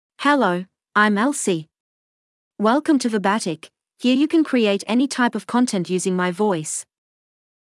FemaleEnglish (Australia)
Elsie is a female AI voice for English (Australia).
Voice sample
Female
Elsie delivers clear pronunciation with authentic Australia English intonation, making your content sound professionally produced.